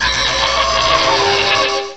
sovereignx/sound/direct_sound_samples/cries/xurkitree.aif at 2f4dc1996ca5afdc9a8581b47a81b8aed510c3a8